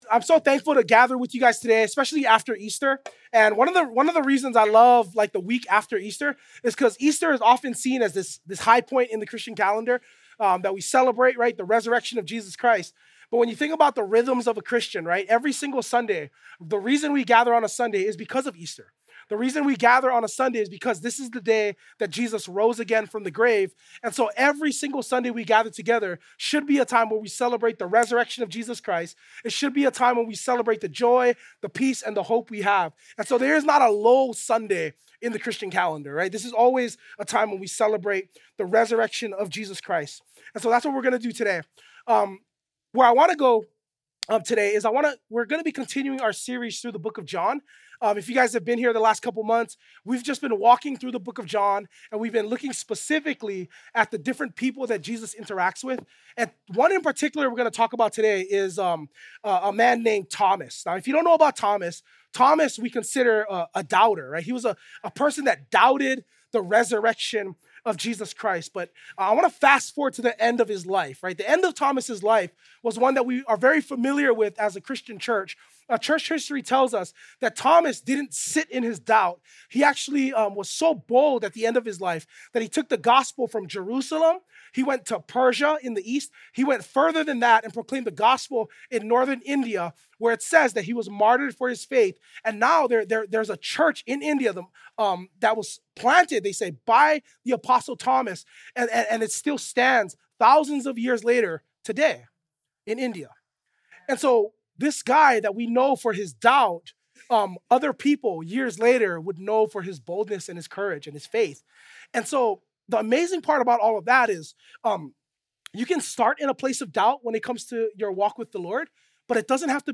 2025 Doubt Transformed John 20:24–31 Preacher